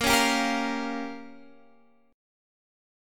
Bbm6 Chord